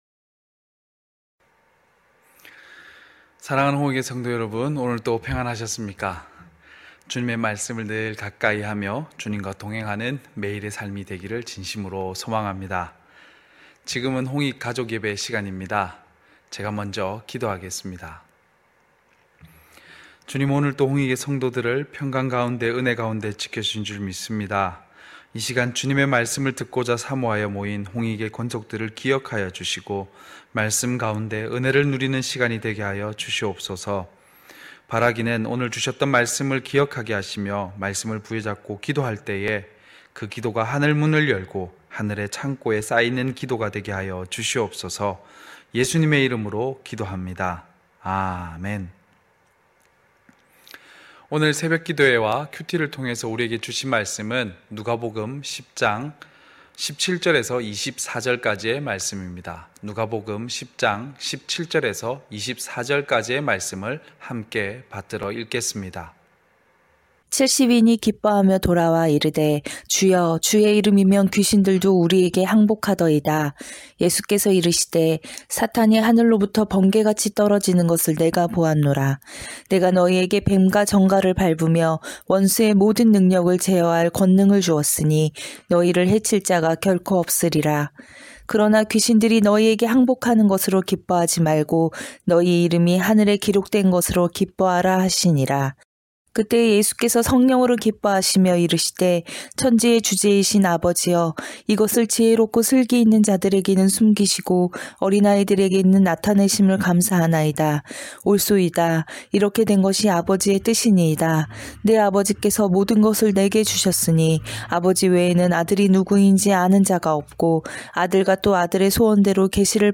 9시홍익가족예배(2월1일).mp3